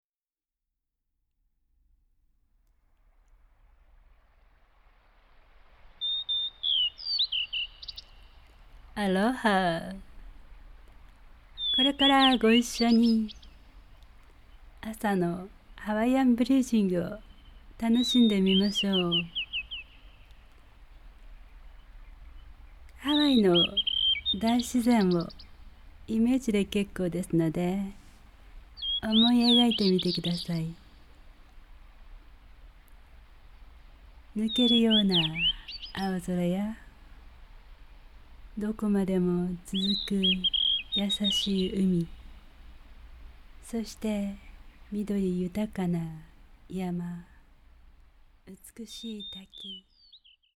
朝のイメージソングは、ハワイの小鳥たちが主体でした
そして小鳥たちのさえずりをバックに呼吸法がナレーターされるCDでした
ねむくなりそうな、とても心地のいい誘導です